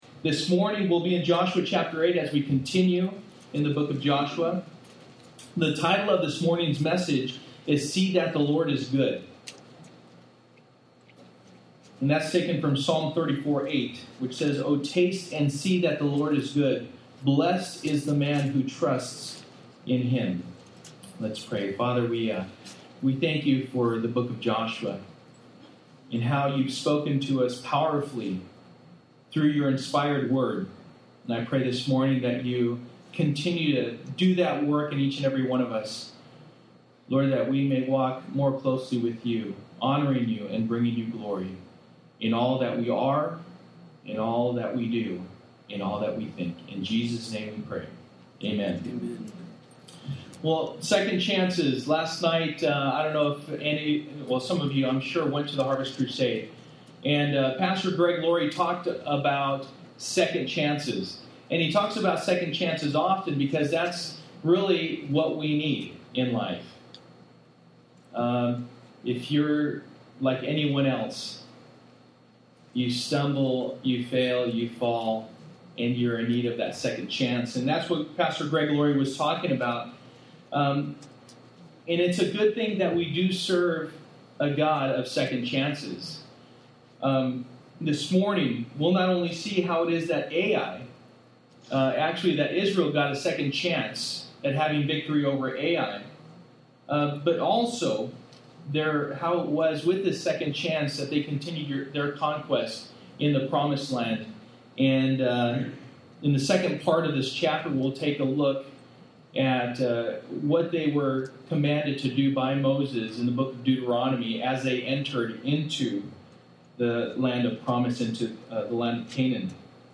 Joshua Passage: Joshua 8:1-35 Service: Sunday Morning %todo_render% « Judgement